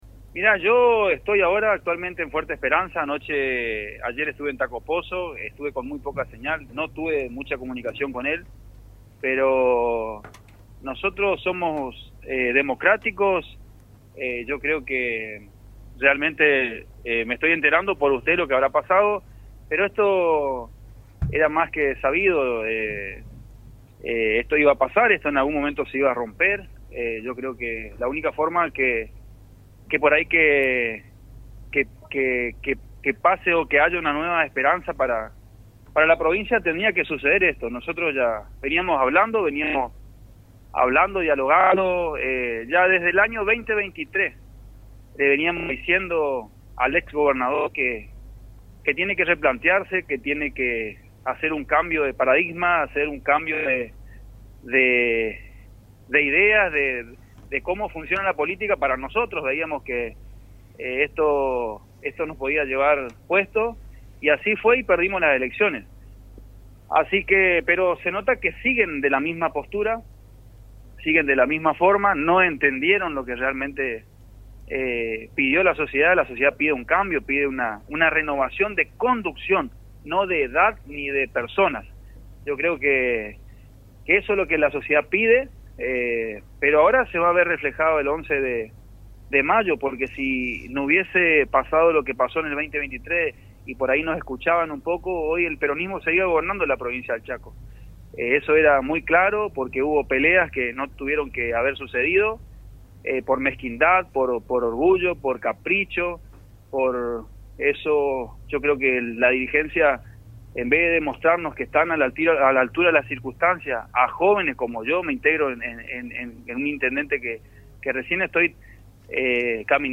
En plena recorrida por el Impenetrable chaqueño, Germán Honcheruk, intendente de Villa Berthet y una de las caras visibles del nuevo frente político Primero Chaco, dialogó con Radio Provincia y no se guardó nada. Con un tono franco, crítico y directo, apuntó contra la conducción del justicialismo chaqueño y contra la gestión actual de gobierno, a la que acusa de repetir los mismos errores que el pasado.